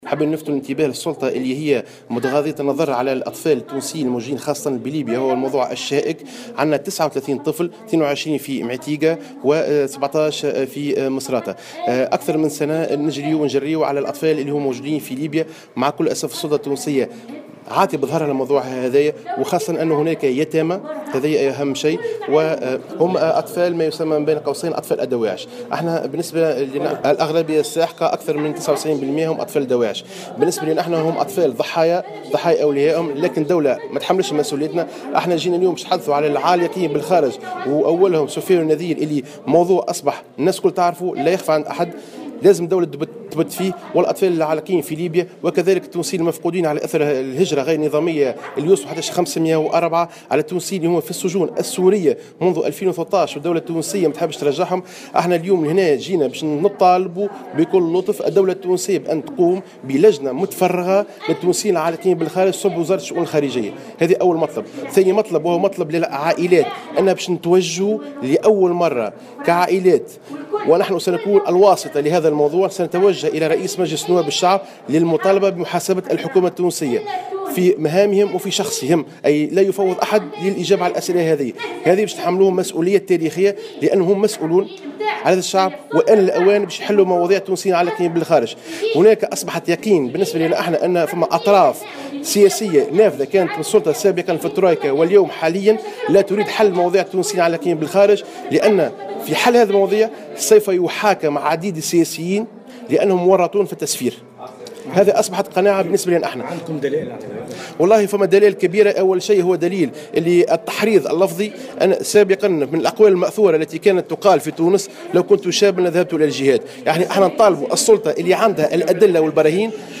تصريح
خلال ندوة صحفية